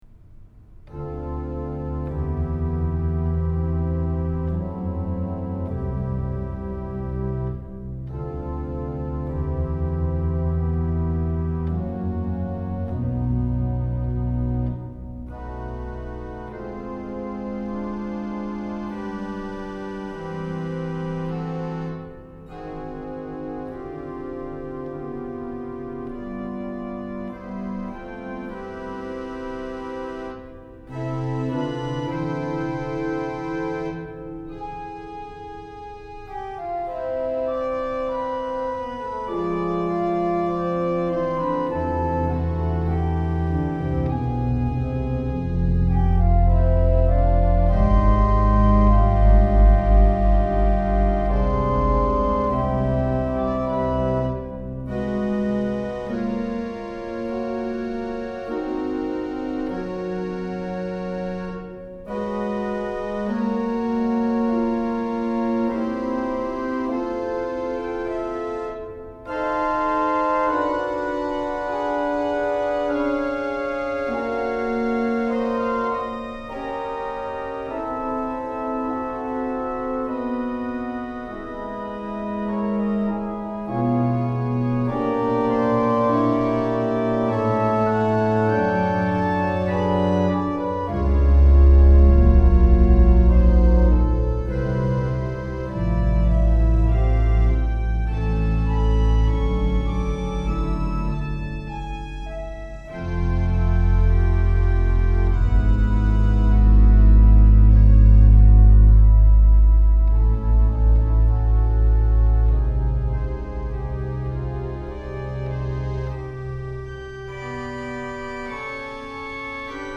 I just pulled it up into Hauptwerk last night and it sounds better on the St. Anne organ.
Here is the piece on the St. Anne Mosley Hauptwerk organ (with added reverb).
duskOnOrgan.mp3